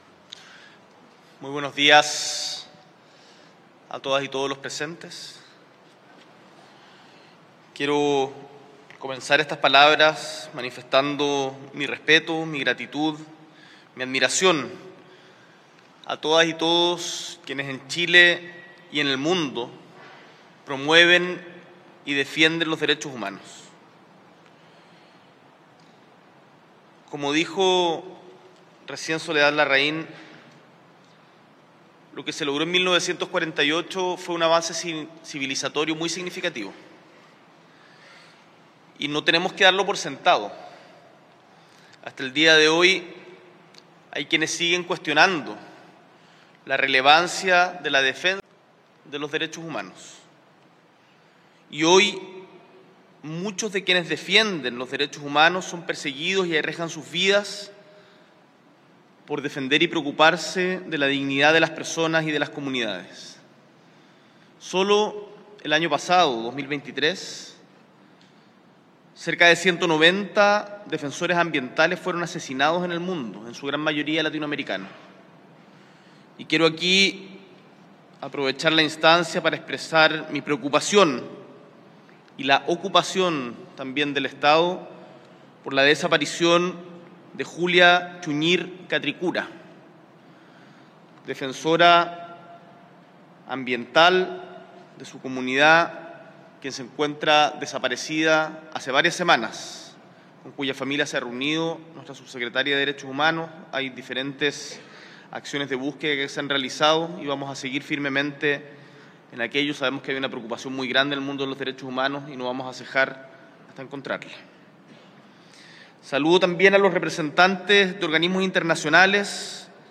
El Mandatario encabezó la ceremonia de conmemoración del Día Internacional de los Derechos Humanos, instancia en la que se firmó el decreto para la creación de la Comisión Asesora Presidencial para esclarecer la verdad de las vulneraciones a los Derechos Humanos de niños, niñas y adolescentes bajo la custodia del Sename.
El martes 10 de diciembre, desde el Palacio de La Moneda, el Presidente de la República, Gabriel Boric Font, encabezó la conmemoración del Día Internacional de los Derechos Humanos, ceremonia en la que se oficializó el inicio del trabajo de la Comisión Asesora Presidencial para esclarecer la verdad de las vulneraciones a los Derechos Humanos de niños, niñas y adolescentes bajo la custodia del Sename.